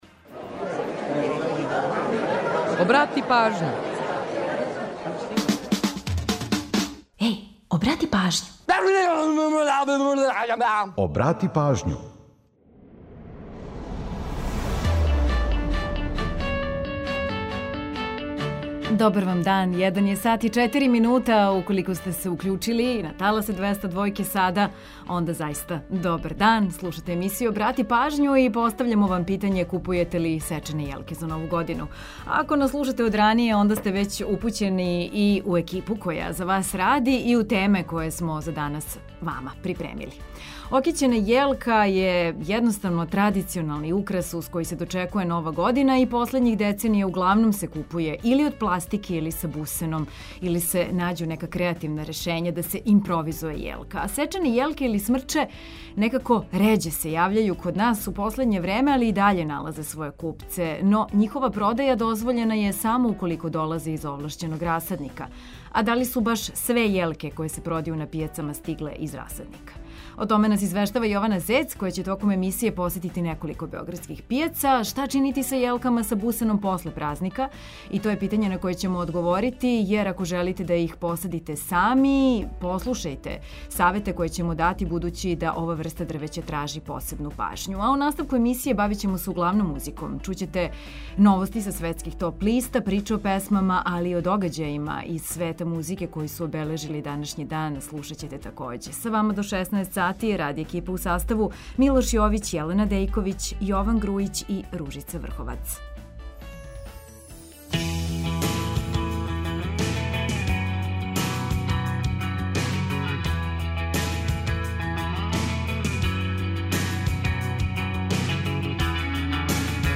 У наставку емисије бавимо се музиком, пре свега.